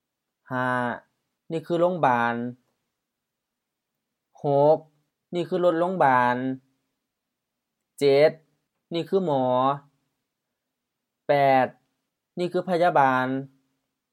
โลงบาน lo:ŋ-ba:n HR-M โรงพยาบาล hospital
ลดโลงบาน lot-lo:ŋ-ba:n H-HR-M รถพยาบาล ambulance
หมอ mɔ: M หมอ 1. doctor, physician
พะยาบาน pha-ya:-ba:n H-M-M พยาบาล nurse